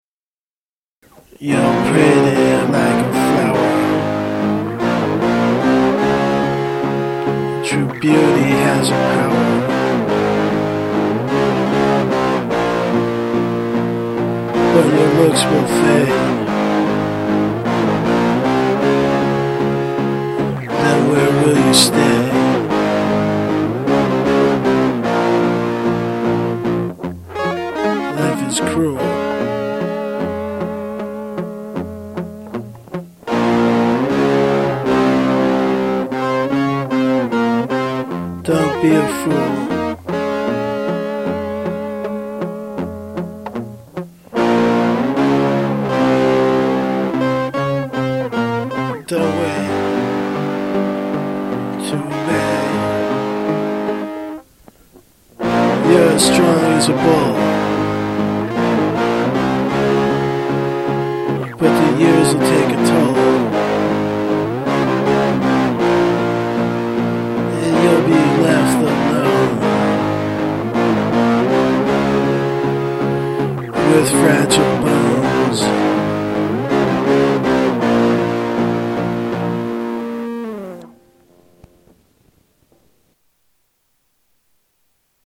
dance/electronic
Punk
Rock & Roll